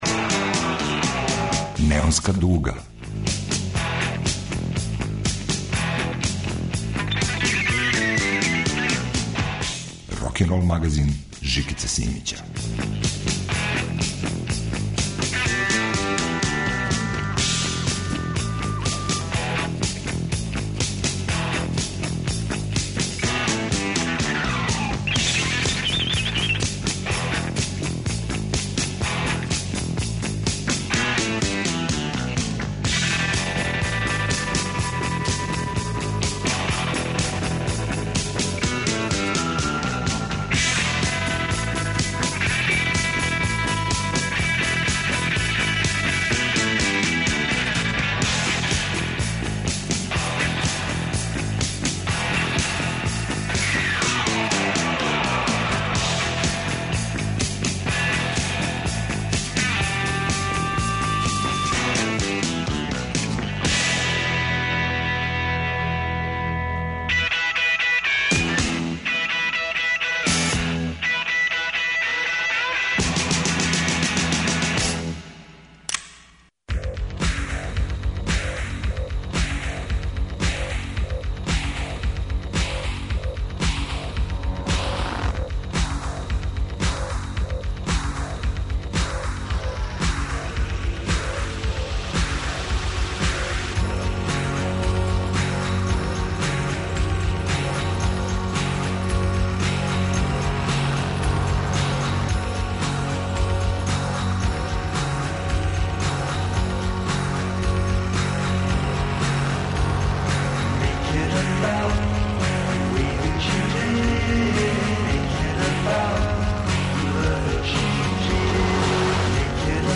Хипици и авантуристи на танкој жици изнад океана звука. Folk N' Roll. Текућа продукција и архивски материјали.
Вратоломни сурф кроз време и жанрове.